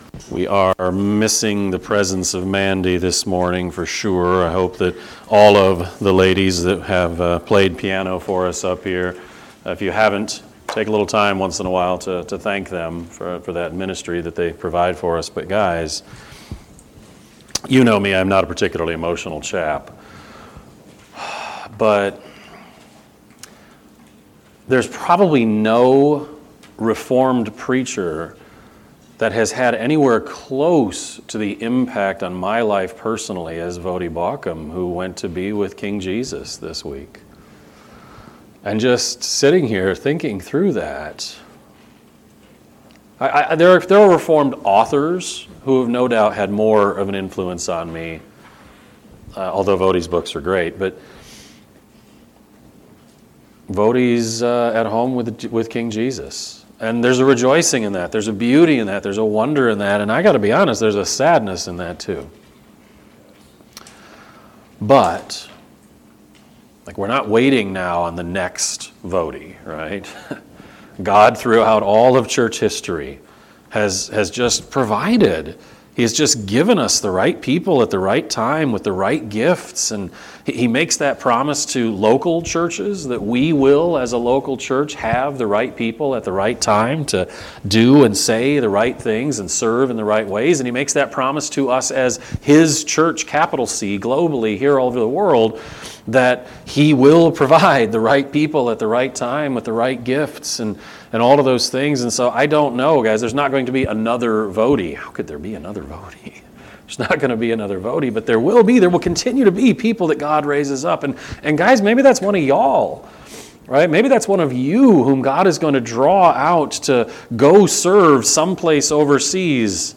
Sermon-9-28-25-Edit.mp3